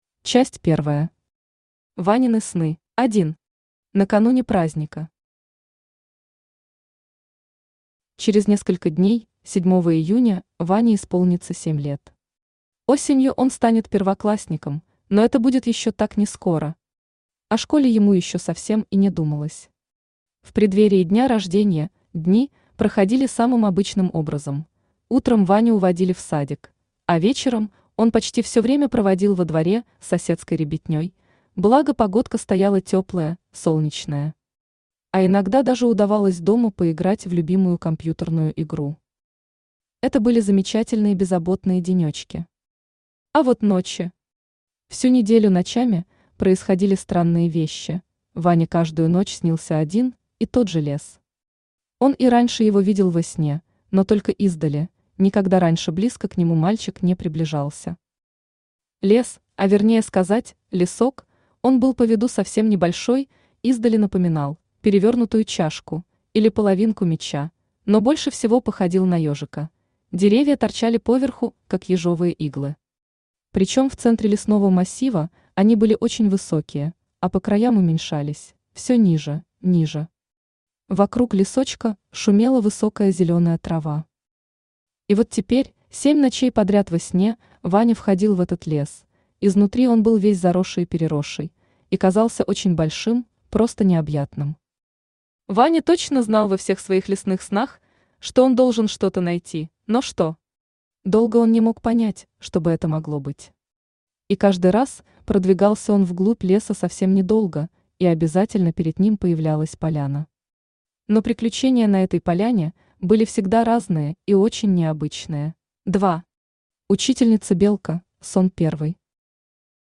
Аудиокнига Великая цифра 7 | Библиотека аудиокниг